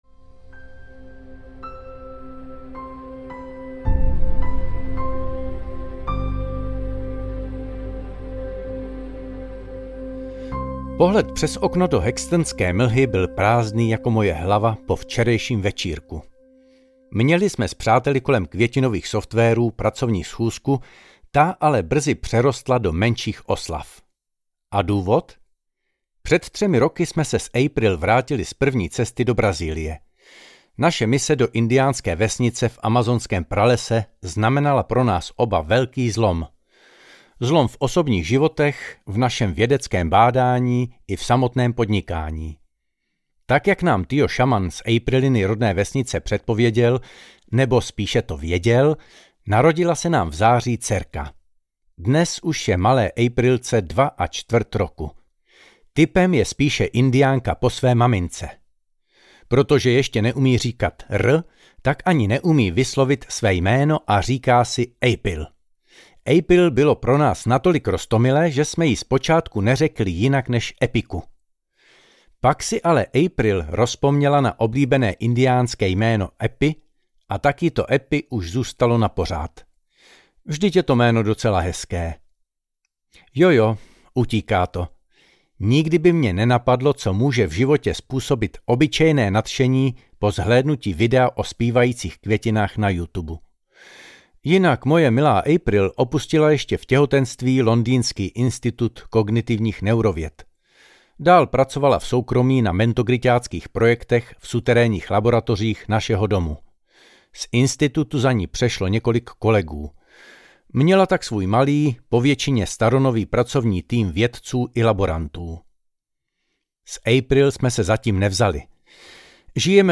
Válka Mentogritů audiokniha
Ukázka z knihy